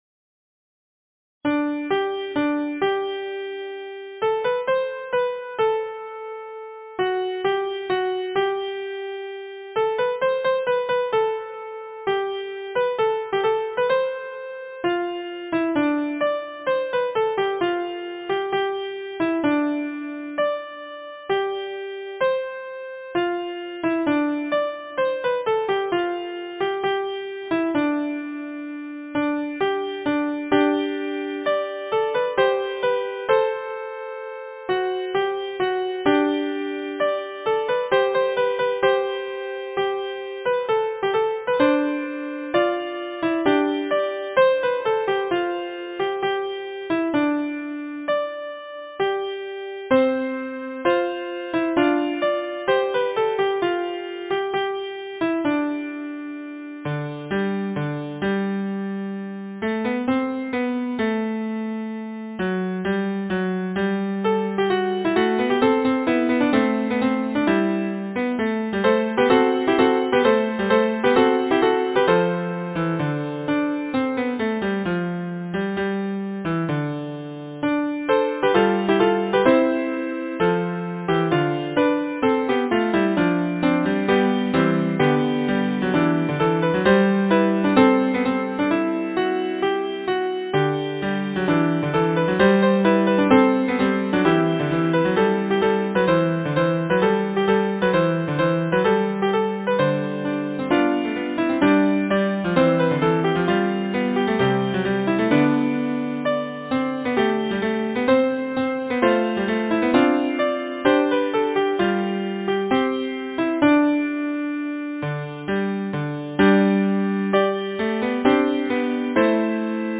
Number of voices: 4vv Voicing: SATB, with very occasional divisi Genre: Secular, Folksong
Language: English Instruments: A cappella